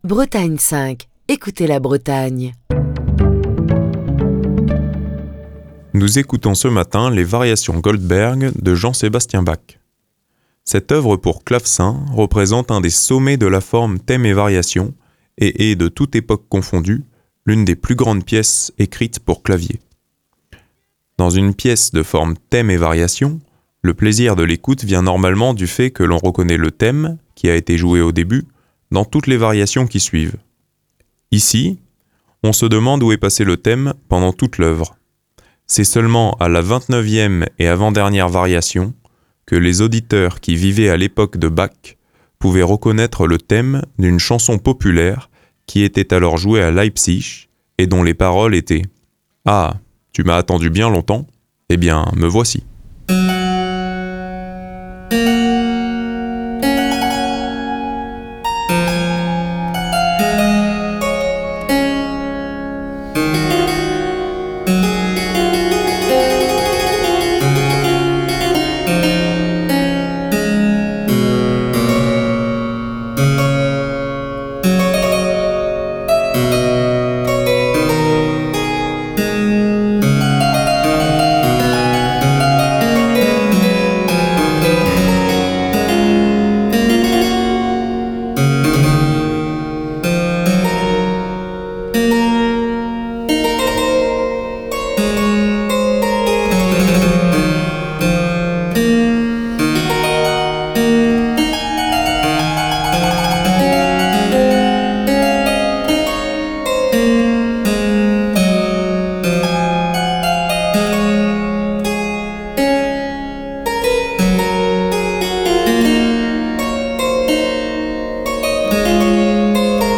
pour clavecin
claveciniste